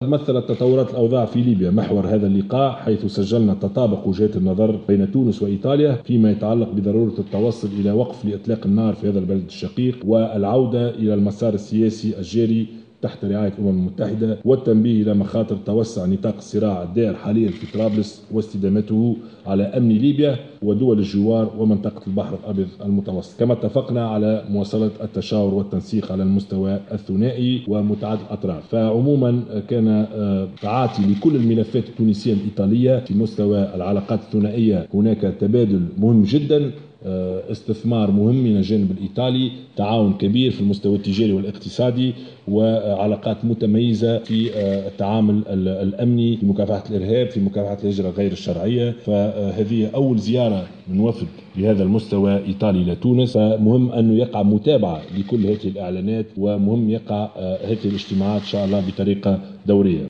قال رئيس الحكومة يوسف الشاهد في في تصريح لمراسلة "الجوهرة أف أم" على هامش ندوة صحفيّة مشتركة بينه وبين نظيره الإيطالي إن اللقاء تمحور خاصة حول تطورات الأوضاع في ليبيا .